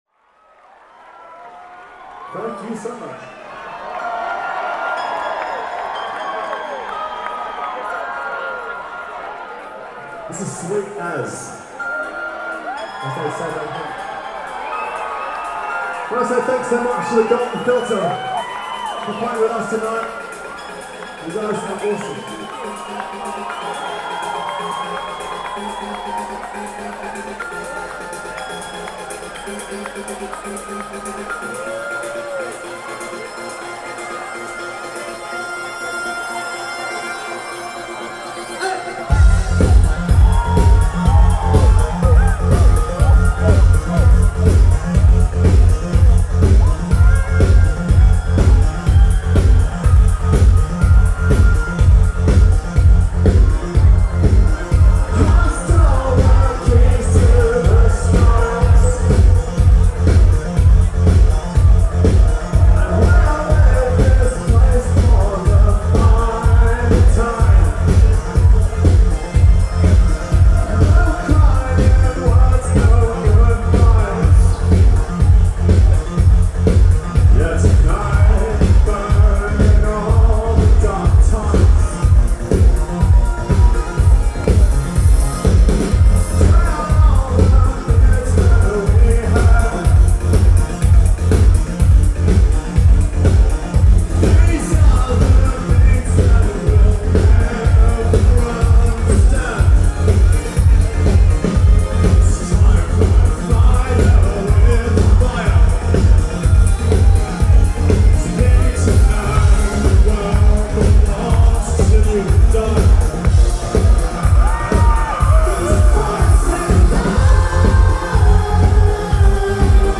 synth-based bands
keyboard driven music